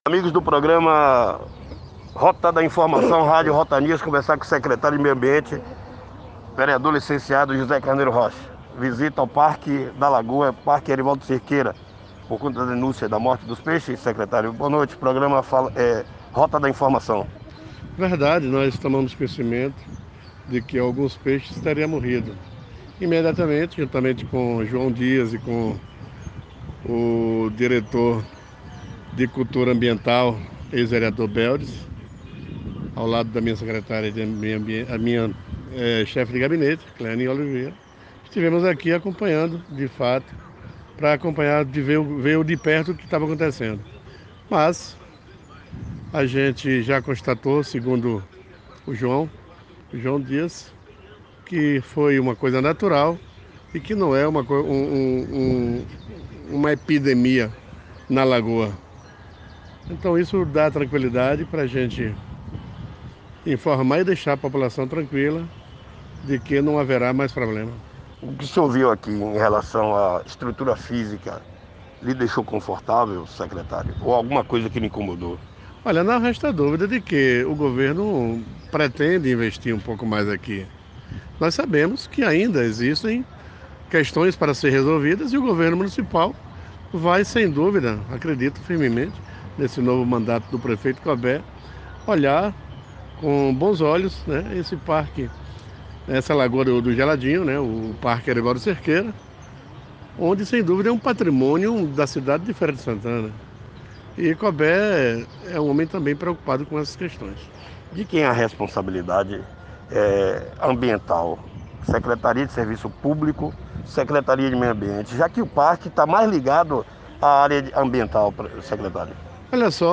Em entrevista ao programa Rota da Informação, o Secretario Zé Carneiro, confirmou a denuncia e falou sobre o assunto.